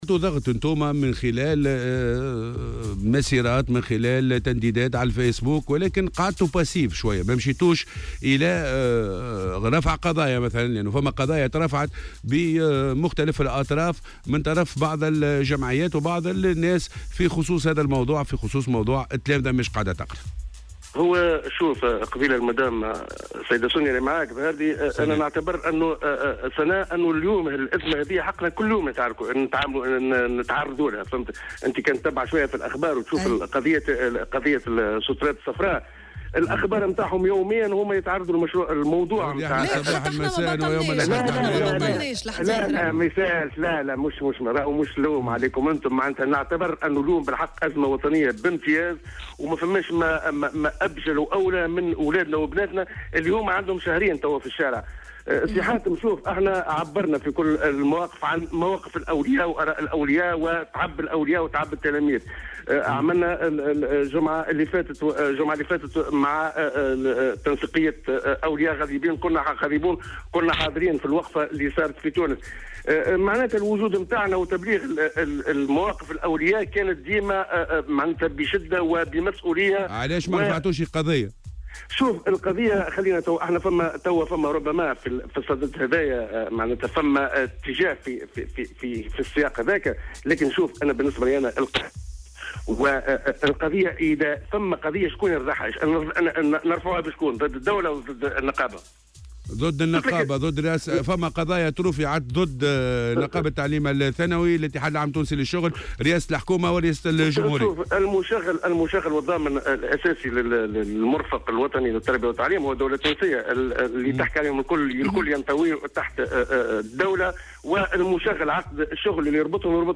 وأضاف في مداخلة له اليوم في برنامج "صباح الورد" على "الجوهرة أف أم" أن الجمعية عبرت في عديد المناسبات عن مواقف الأولياء واستيائهم من تواصل هذه الأزمة و خطورة الوضعية.